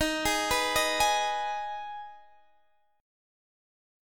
Ebsus4#5 chord